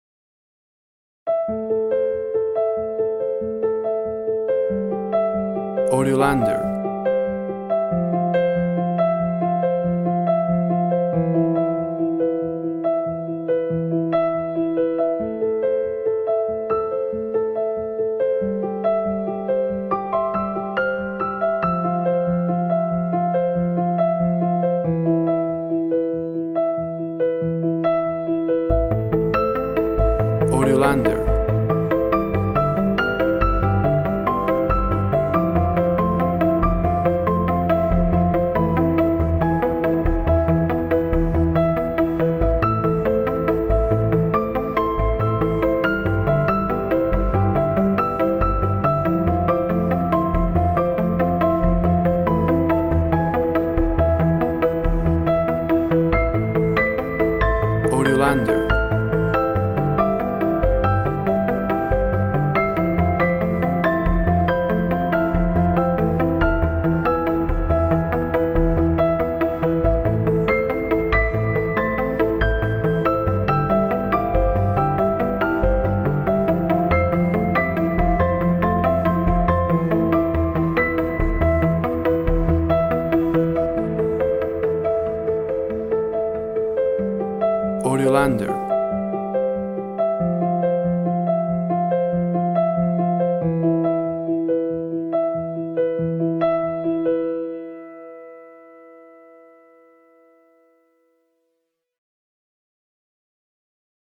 Quiet musical piano emotional play.
Tempo (BPM) 120